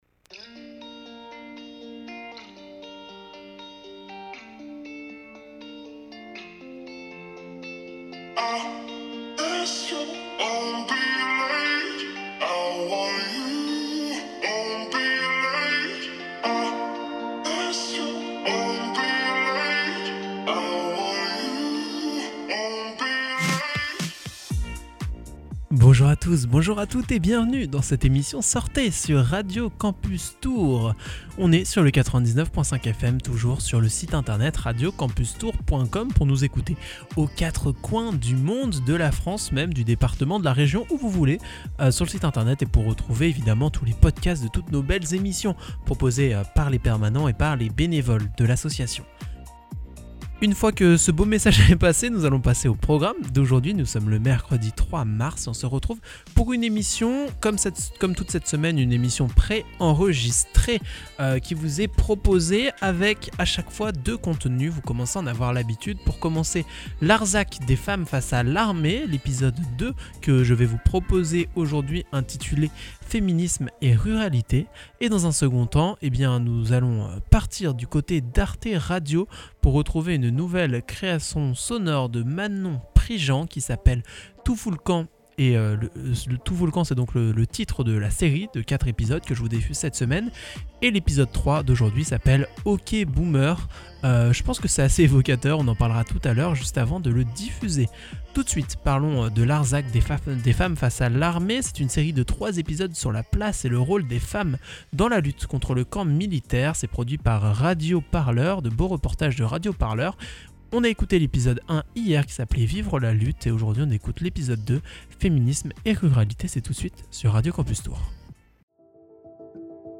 Cette semaine, je vous propose 4 émissions de Sortez! pré-enregistrées.